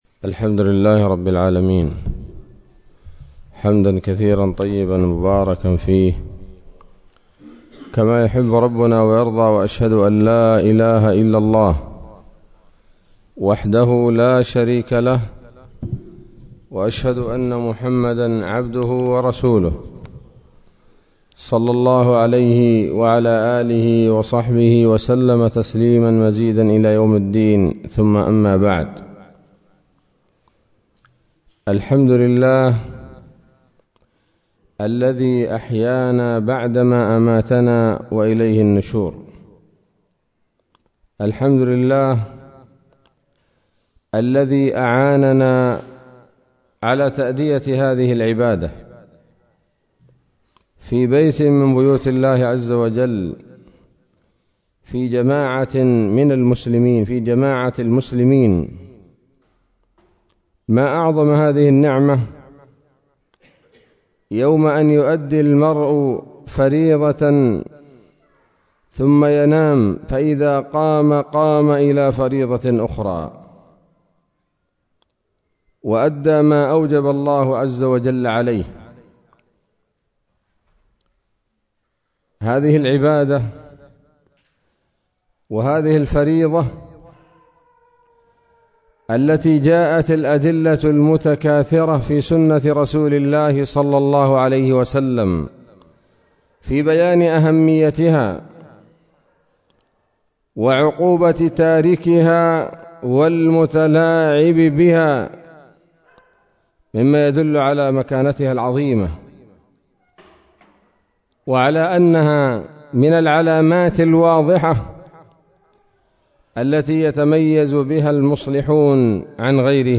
كلمة قيمة بعنوان: (( الصلاة الصلاة وما ملكت أيمانكم )) فجر السبت 8 ربيع الآخر 1443هـ، بمسجد الصحابة - بمنطقة باب المندب